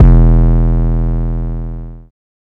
GERM 808.wav